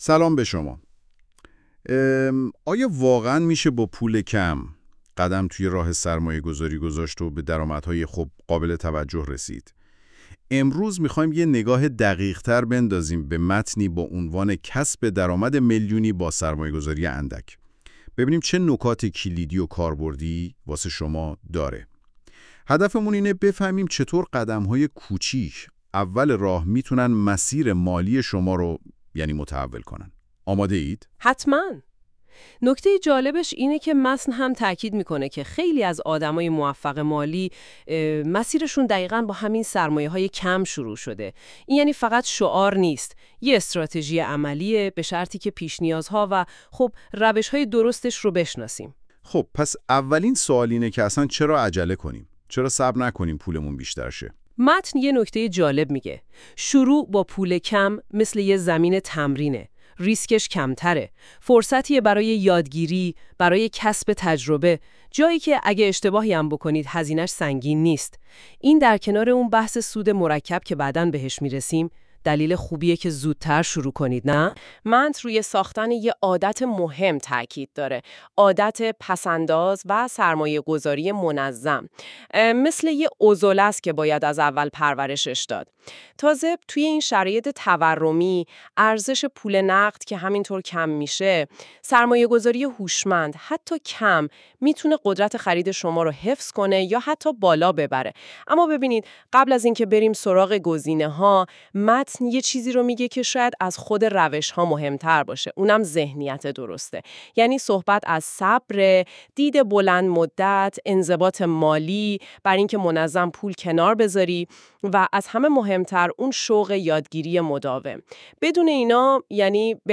🎧 خلاصه صوتی بهترین روش های سرمایه گذاری با پول کم
این خلاصه صوتی به صورت پادکست و توسط هوش مصنوعی تولید شده است.